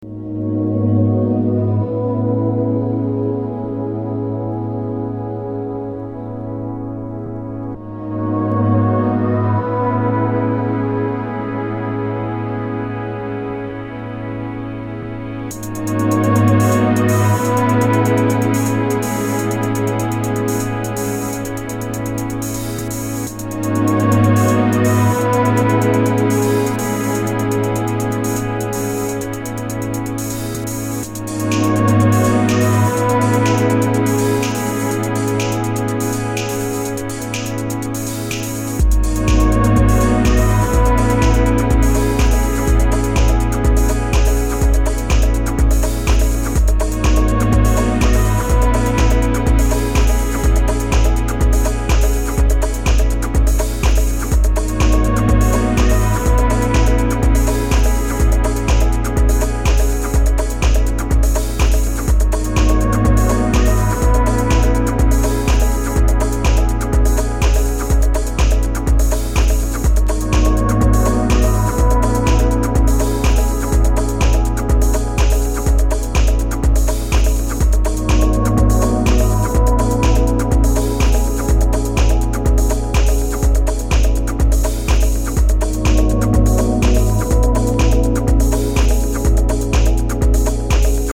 melancholic modern house
with its impressive melancholy strings